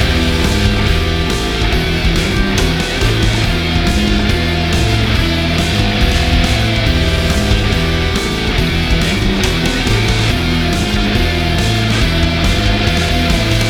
Warning: This artwork incorporates iAMF frequency elements intended for subconscious conditioning.
These ‘Infinity L00p’ soundscapes, crafted with precision, resonate with the listener’s subconscious, creating an auditory experience that lingers long after the final note.